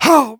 Voice file from Team Fortress 2 Russian version.
Spy_painsharp03_ru.wav